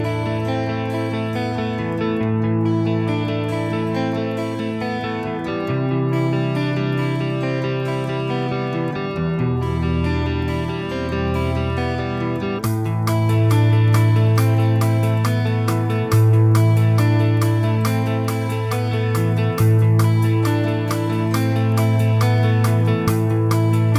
no Backing Vocals Indie / Alternative 4:13 Buy £1.50